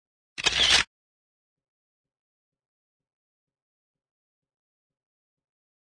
Звук скриншота